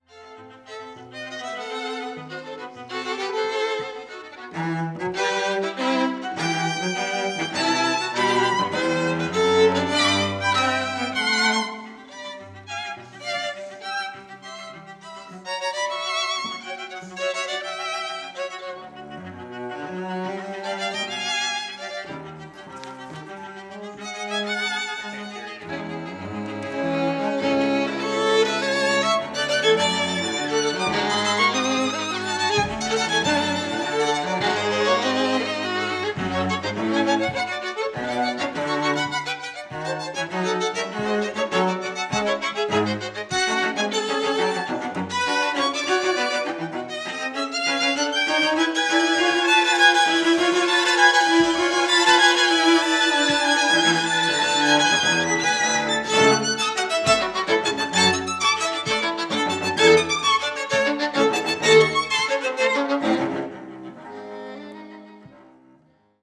Tetrahedral Ambisonic Microphone
Recorded February 4, 2010, Jessen Auditorium, University of Texas at Austin. Quartet practicing for recording session.
Credits: Aeolus String Quartet.